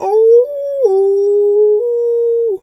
wolf_2_howl_soft_02.wav